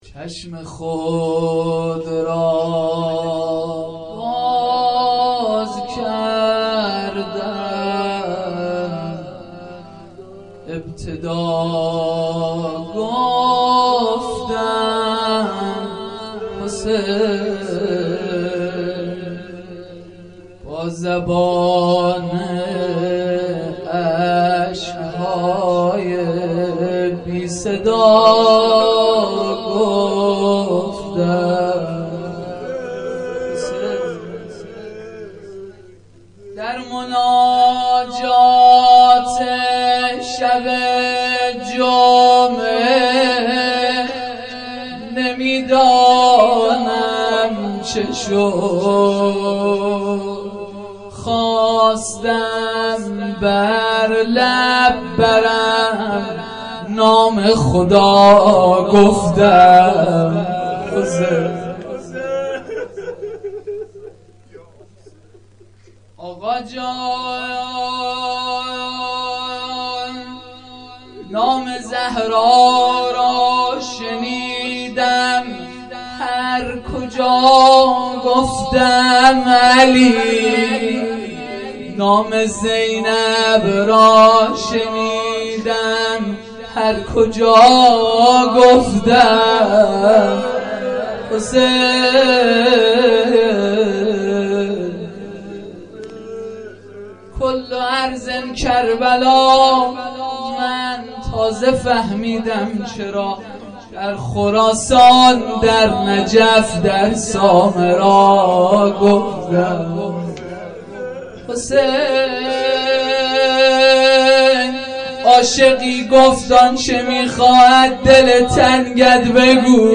جلسه مذهبی زیارت آل یاسین باغشهر اسلامیه